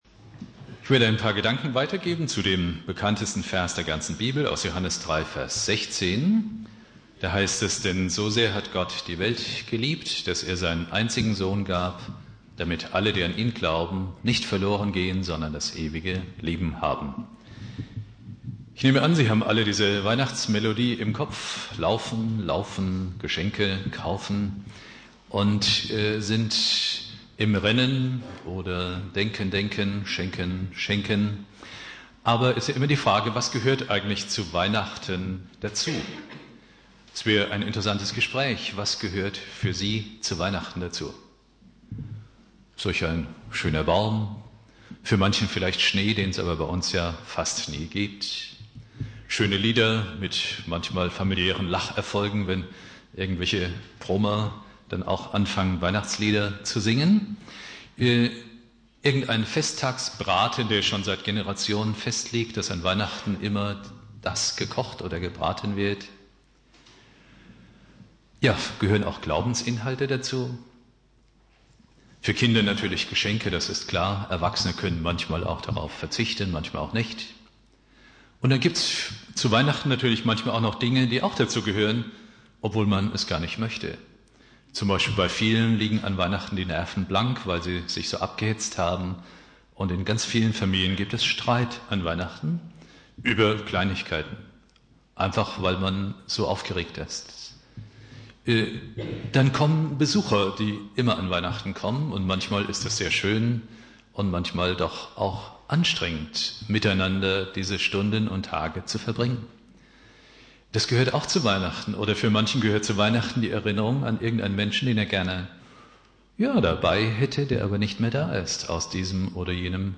Predigt
Heiligabend